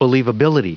Prononciation du mot believability en anglais (fichier audio)
Prononciation du mot : believability